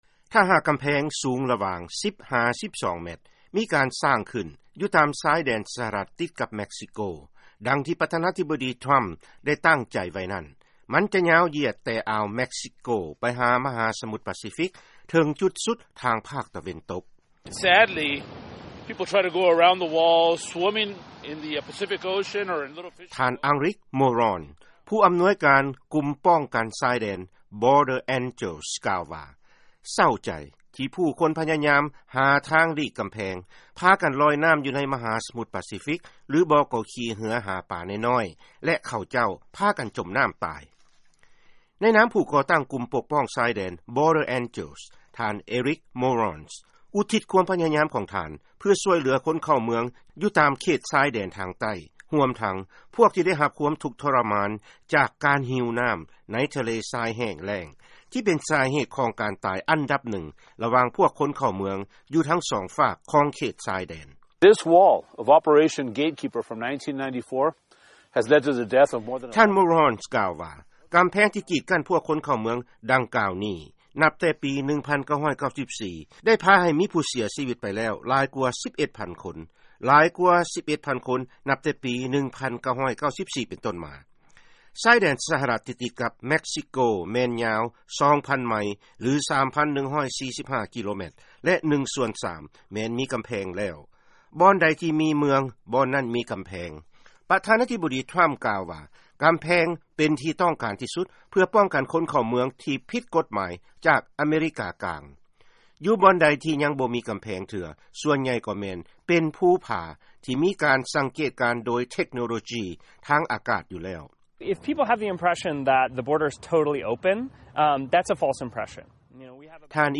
ເຊີນຟັງລາຍງານ ກ່ຽວກັບການສ້າງກຳແພງ ລະຫວ່າງຊາຍແດນ ສະຫະລັດ ກັບເມັກຊິໂກ